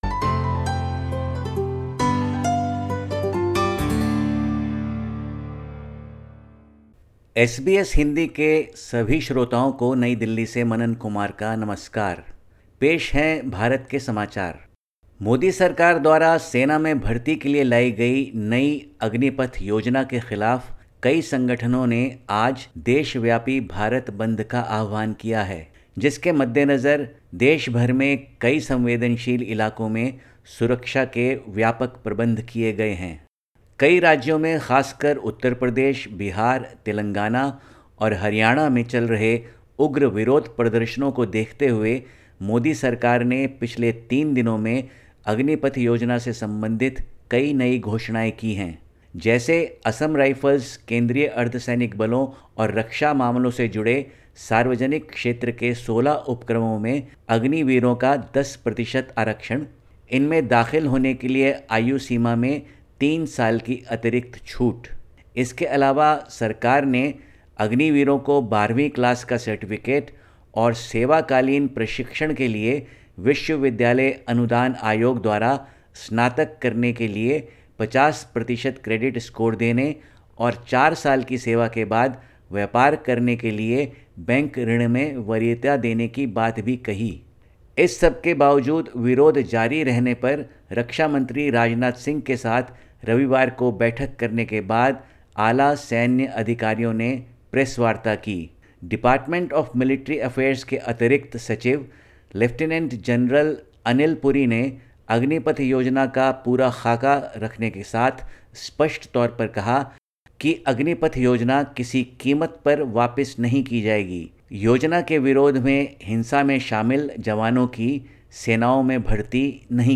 Listen to the latest SBS Hindi report from India. 20/06/2022